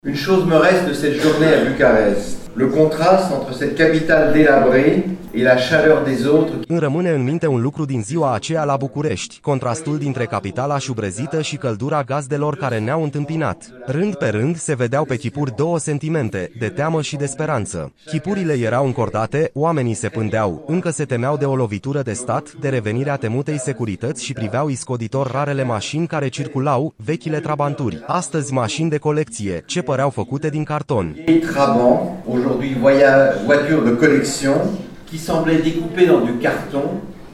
Scriitorul și eseistul francez Pascal Bruckner a primit astăzi titlul de Doctor Honoris Causa al Universității de Vest din Timișoara.
În discursul său, scriitorul francez a vorbit publicului despre prima sa interacțiune cu România, în anul 1990 și ce a văzut pe străzile capitalei, într-o țară cutremurată de schimbarea de regim.
05-Pascal-Bruckner-DUBLAT-17-1.mp3